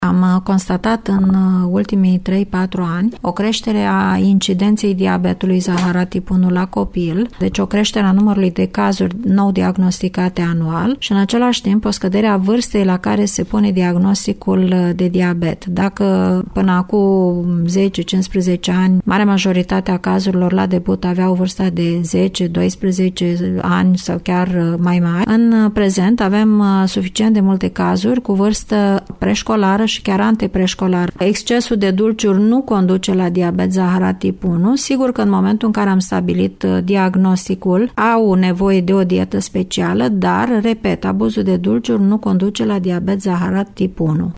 Ea a precizat că excesul de dulciuri nu reprezintă cauza acestei boli: